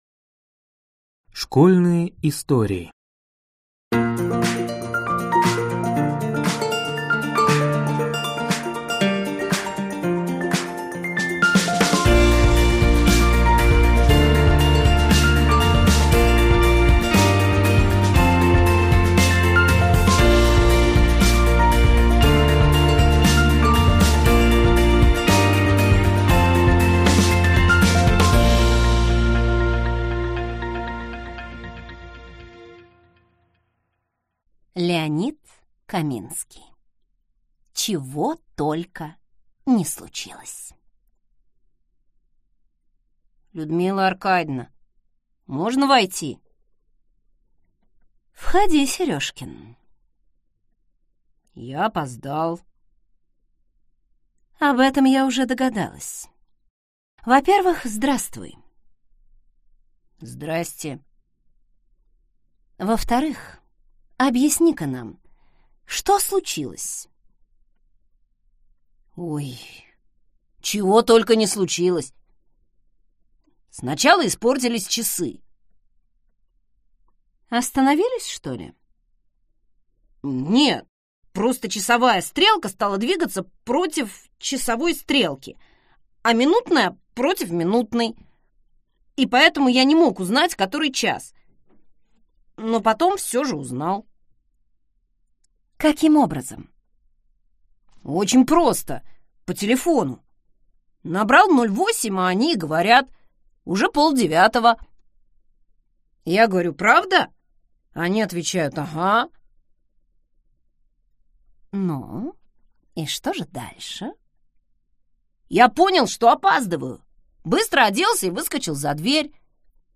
Аудиокнига Школьные истории | Библиотека аудиокниг